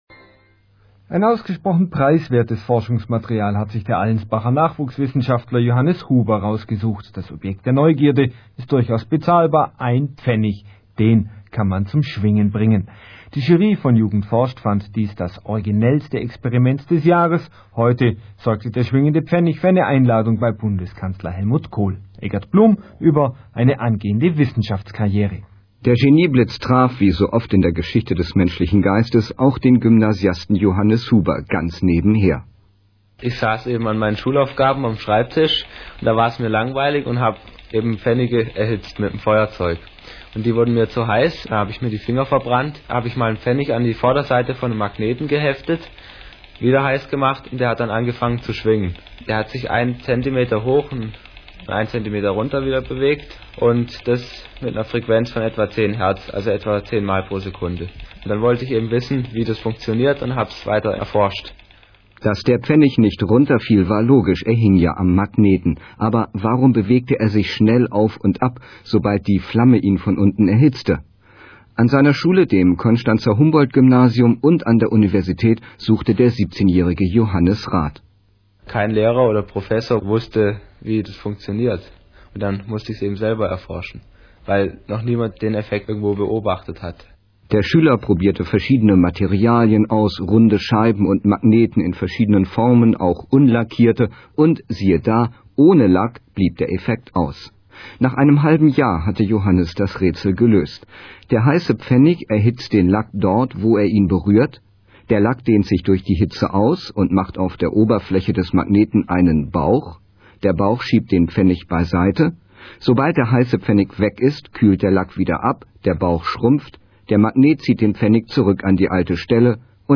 Bayern3-Radiointerview